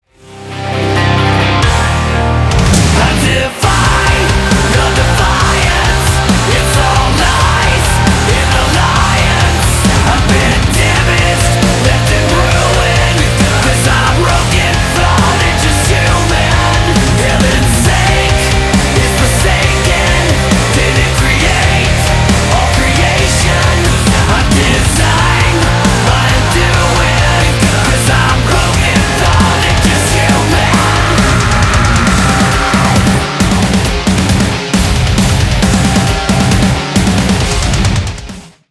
• Качество: 320, Stereo
мужской вокал
громкие
мощные
Драйвовые
Alternative Metal
heavy Metal
groove metal